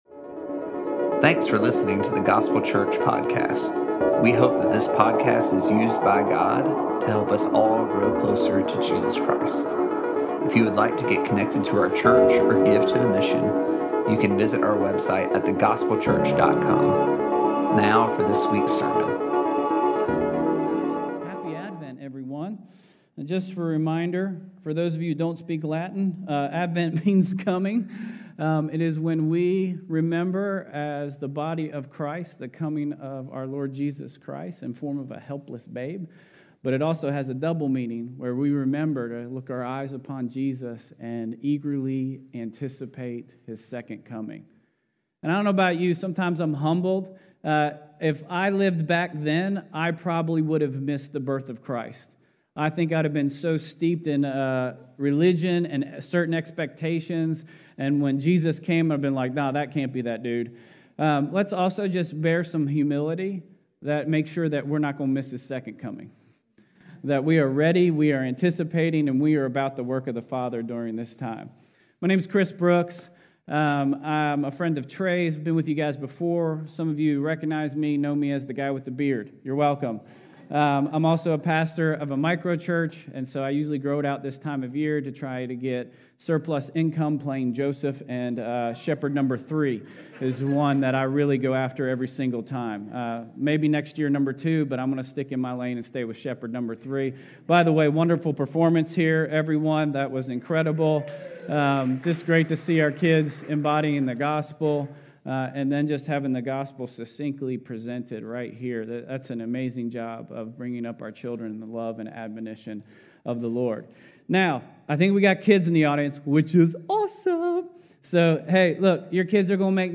continues our We Receive series by preaching on the love that we receive through Christ.